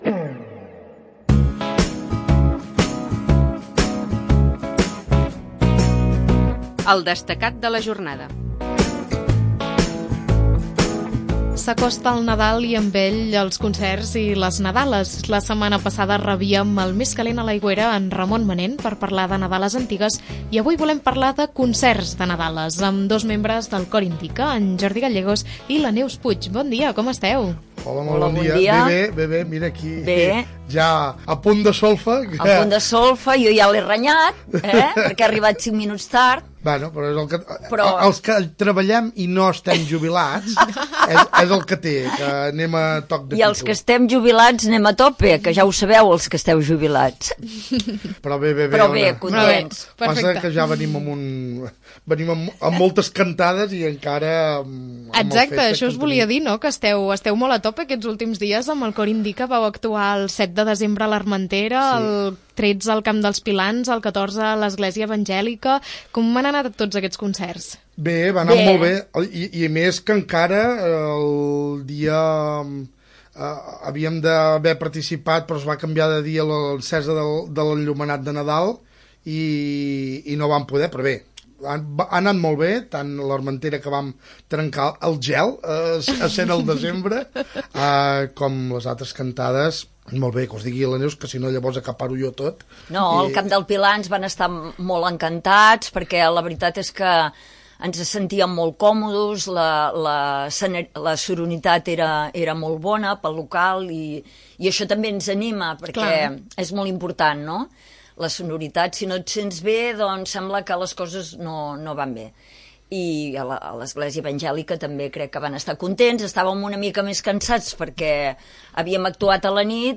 En parlem amb dos components